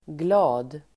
Uttal: [gla:d]